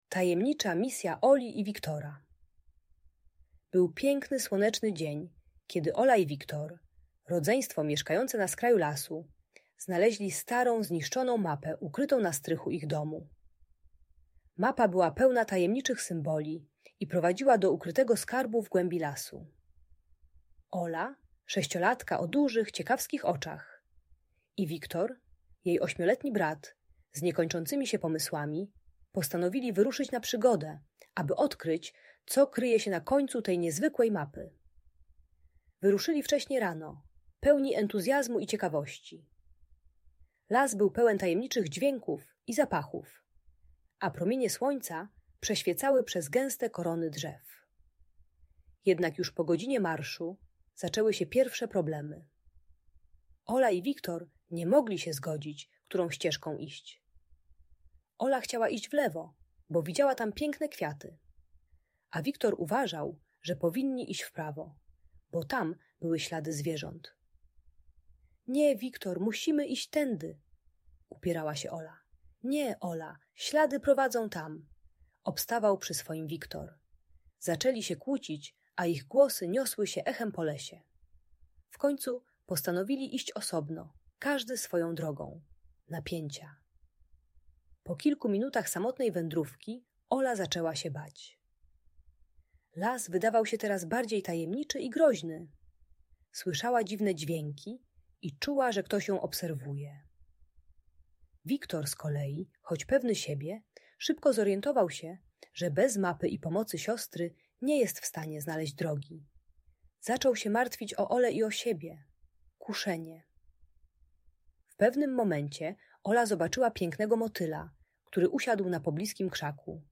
Tajemnicza Misja Oli i Wiktora - opowieść o przygodzie i współpracy - Audiobajka